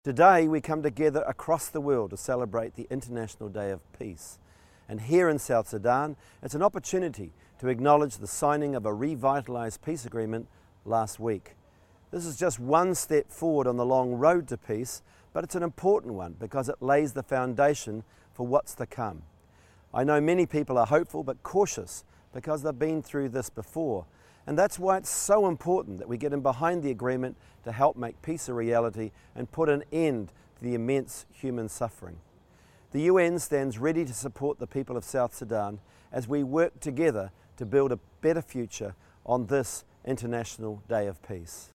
SRSG David Shearer Message on International Day of Peace